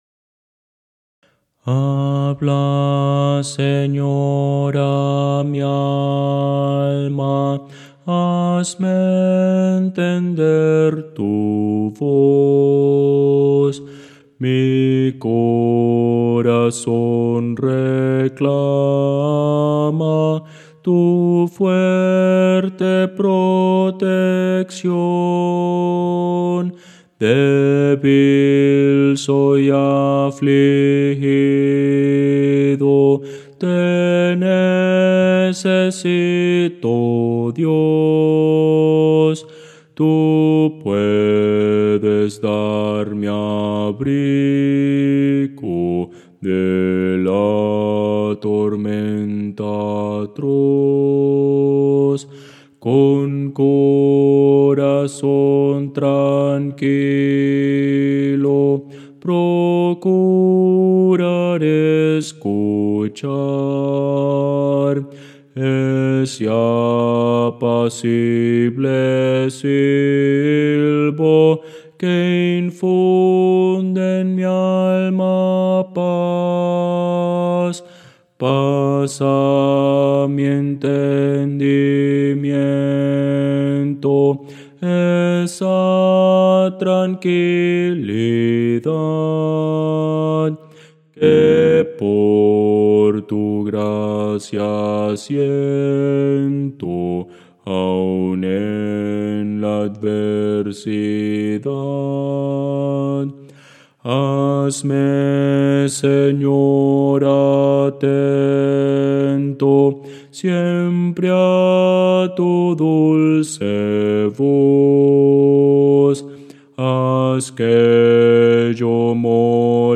Música: MIDI
Voces para coro